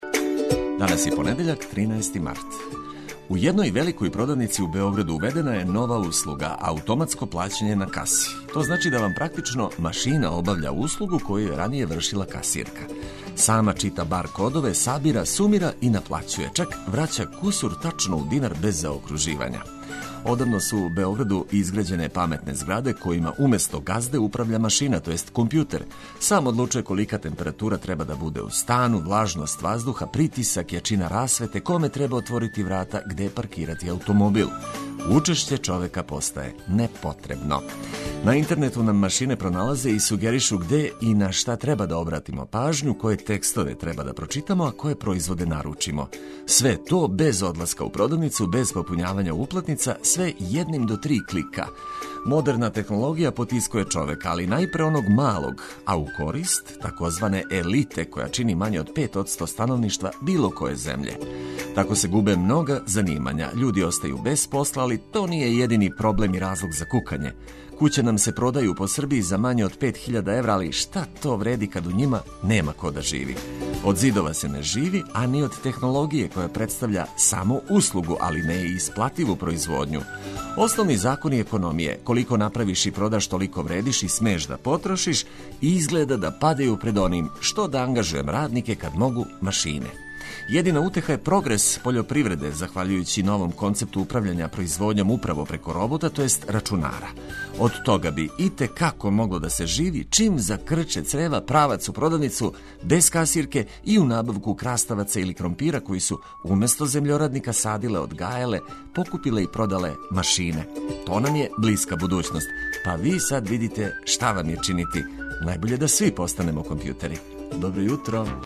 Ову седмицу почињемо радно, али уз музику и добро расположење за лепши почетак јутра.